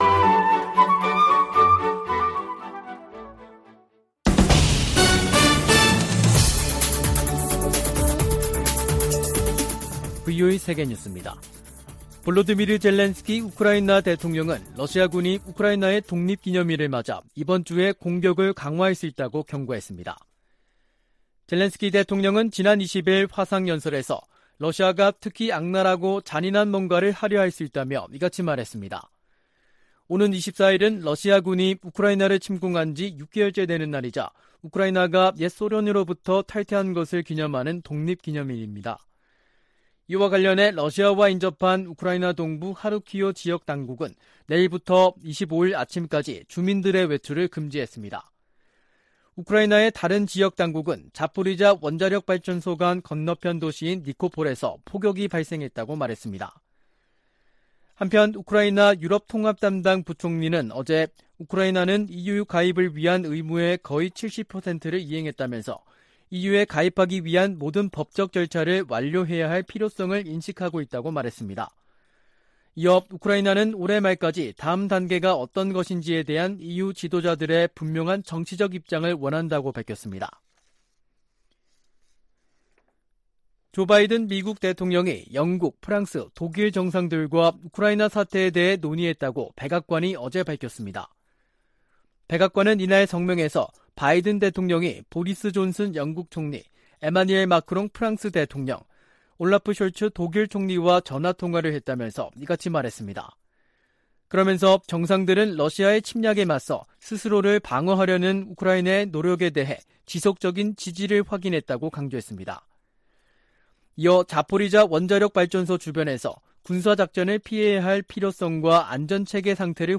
VOA 한국어 간판 뉴스 프로그램 '뉴스 투데이', 2022년 8월 22일 2부 방송입니다. 미군과 한국군이 을지프리덤실드(UFS) 연합훈련을 시작했습니다. 토니 블링컨 미 국무부 장관이 박진 한국 외교부 장관과의 통화에서 대일 관계 개선과 한반도 비핵화 노력에 대한 윤석열 대통령의 광복절 경축사에 감사를 표했다고 국무부가 전했습니다. 유엔 인권기구는 엘리자베스 살몬 북한 인권 특별보고관이 오는 29일 한국을 공식 방문합니다.